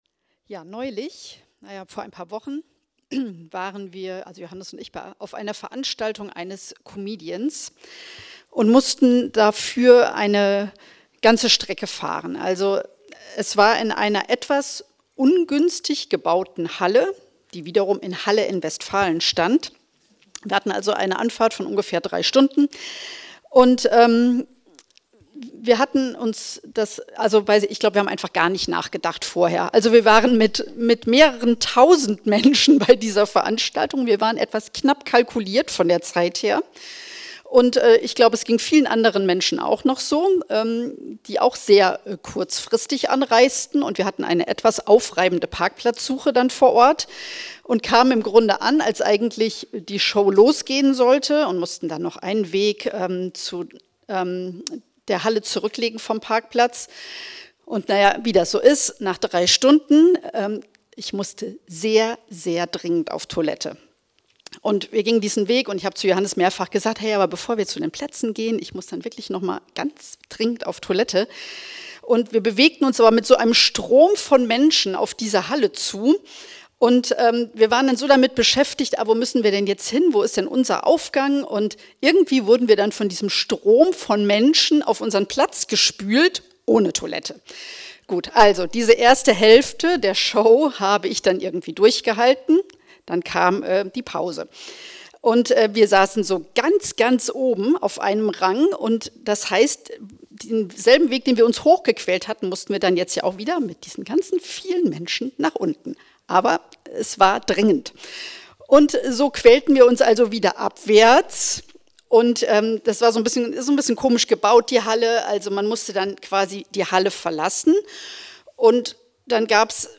Hoffnungsvolles Warten ~ Anskar Wetzlar // Predigt Podcast